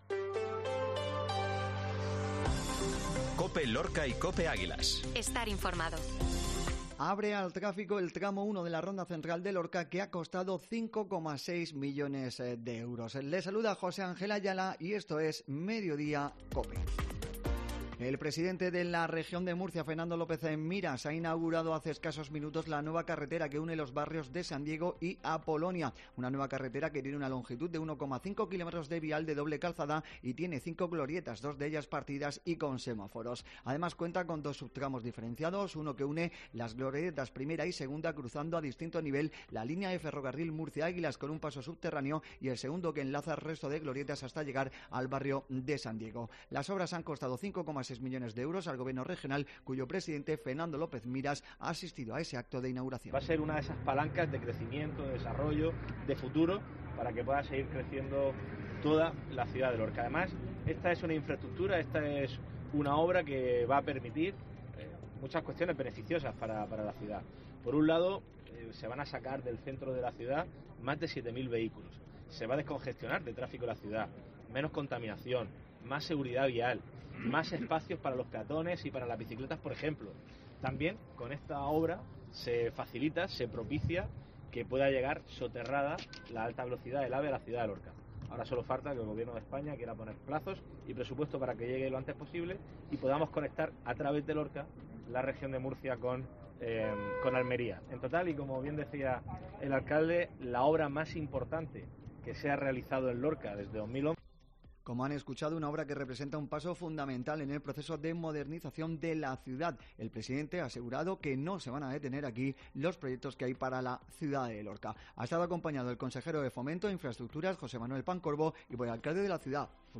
INFORMATIVO MEDIODÍA